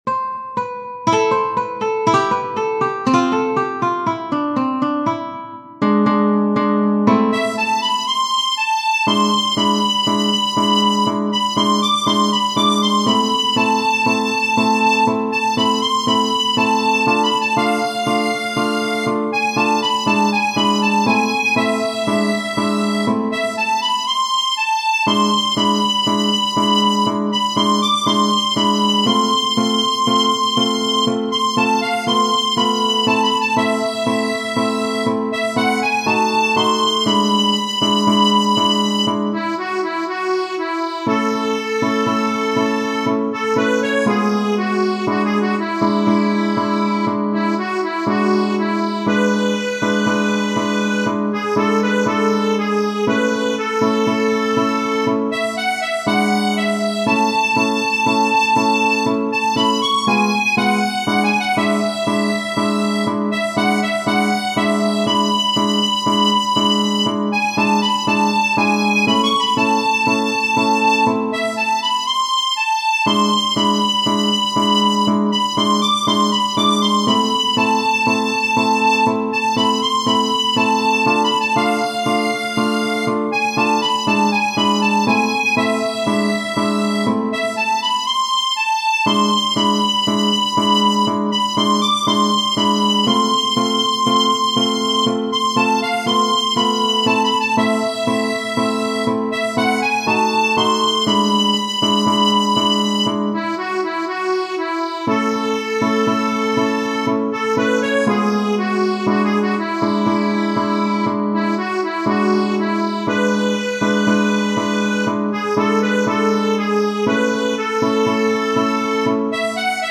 Genere: Ballabili
è una canzone a ritmo di tango